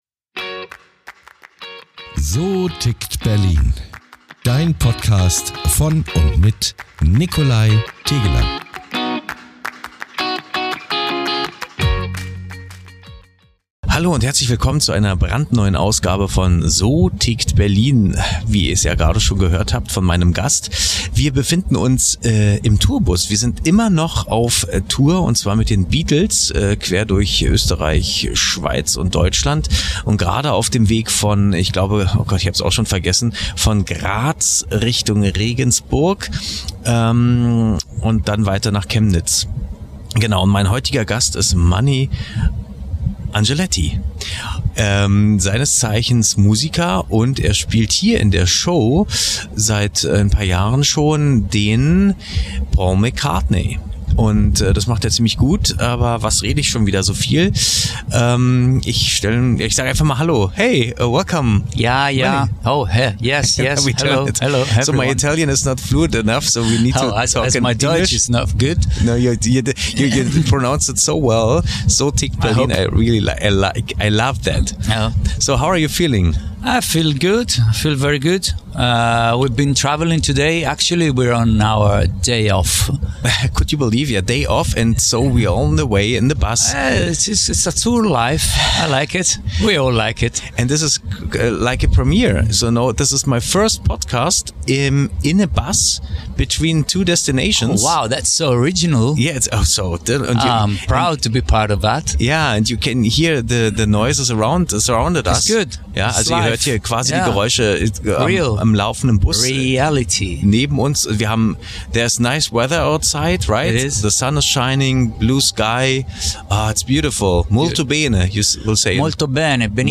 Außerdem erfahren wir, wie es sich anfühlt, als Musiker in die Fußstapfen eines der größten Künstler aller Zeiten zu treten. Ein spannendes Gespräch über Leidenschaft, Perfektion und den schmalen Grat zwischen Tribute und Identität.